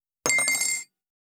254,カトラリーの音,食器の音,会食の音,食事の音,カチャン,コトン,効果音,環境音,BGM,カタン,チン,コテン,コン,カチャ,チリ,チャリン,カラン,トン,シャリン,チャッ,カツン,コンッ,タリン,チョン,カチッ,カランカラン,
コップ